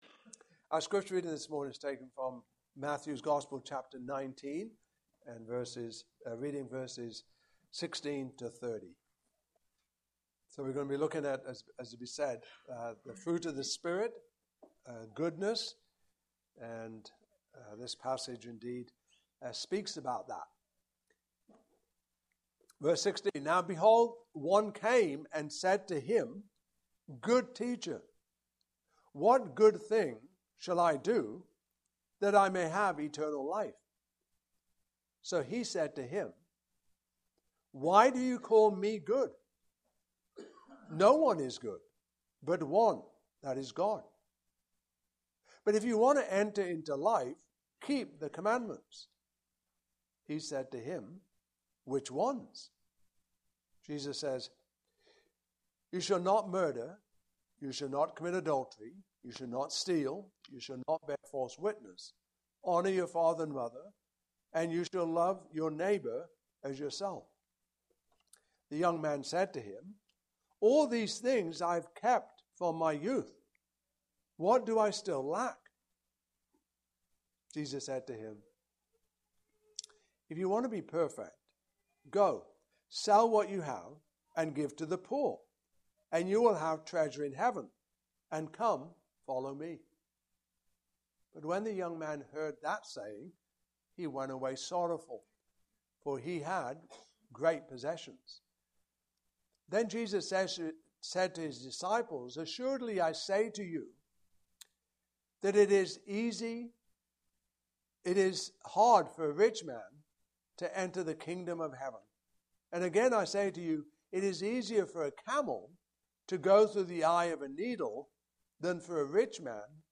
Passage: Matthew 19:16-30 Service Type: Morning Service